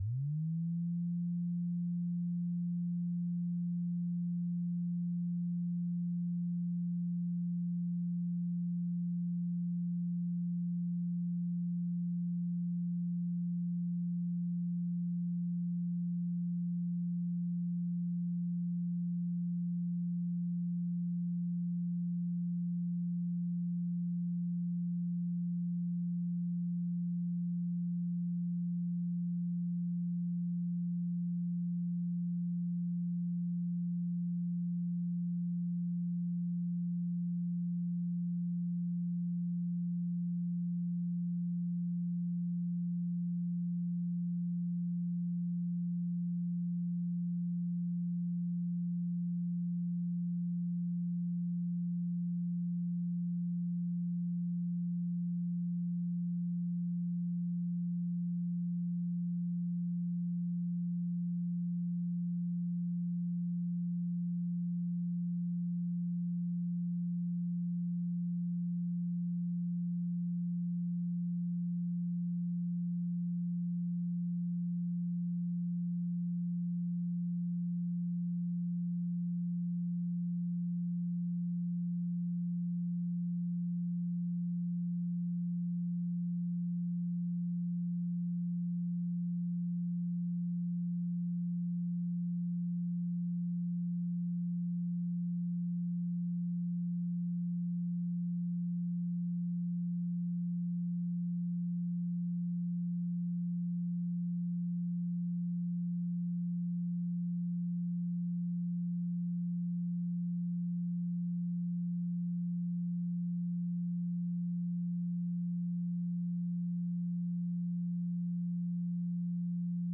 174 Hz Tone Sound Solfeggio Frequency
Solfeggio Frequencies